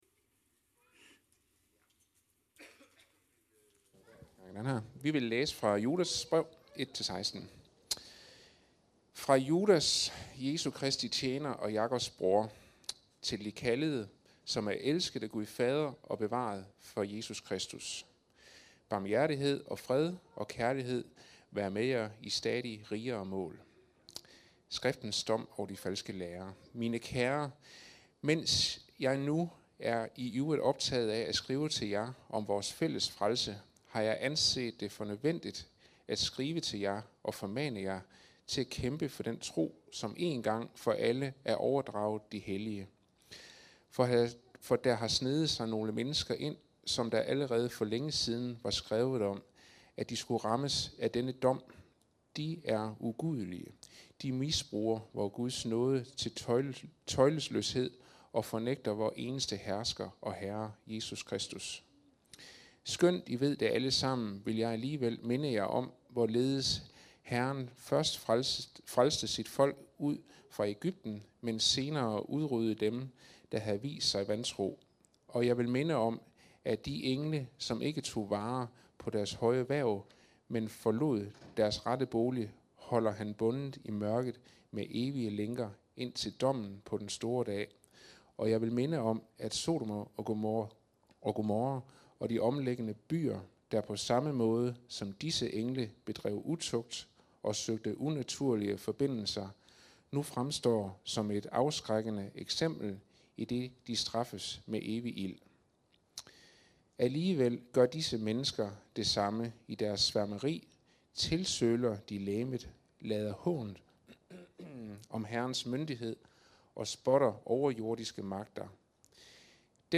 Kæmp for troen (Judas brev v1-16) – Undervisning